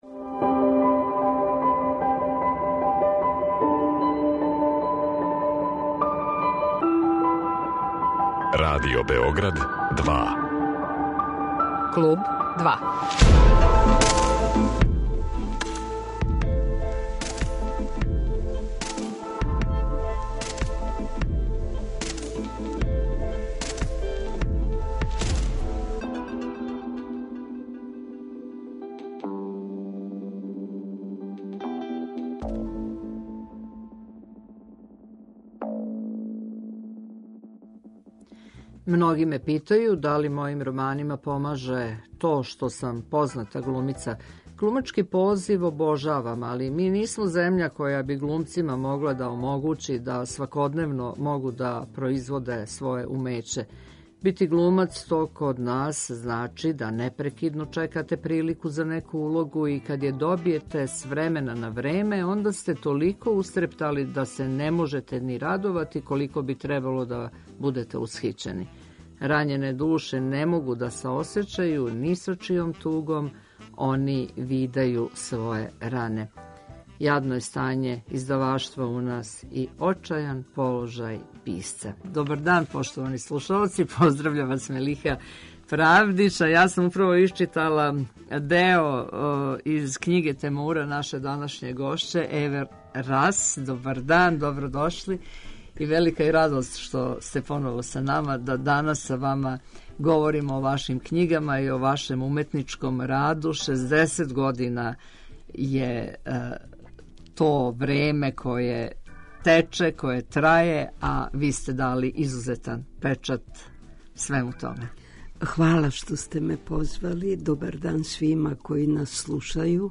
Гошћа Клуба 2 је драмски уметник и писац Ева Рас за коју је прошла година била јубиларна-напунила је 60 година уметничког рада.